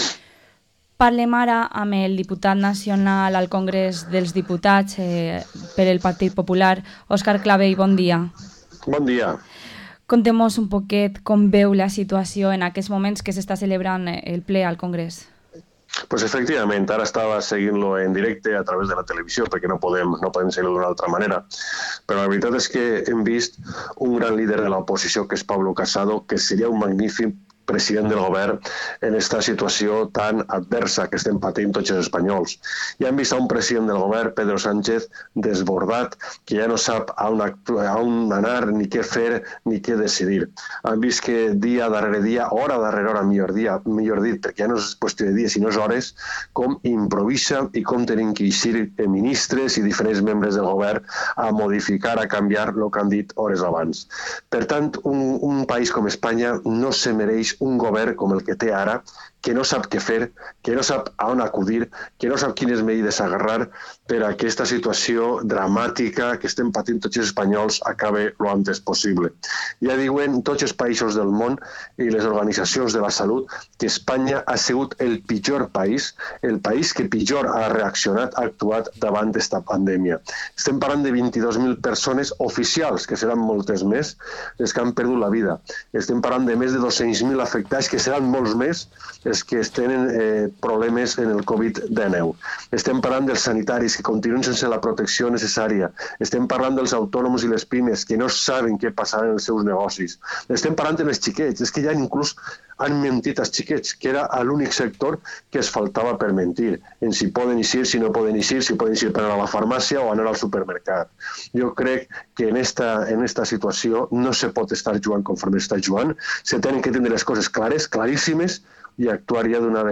Entrevista al diputado nacional del Partido Popular, Oscar Clavell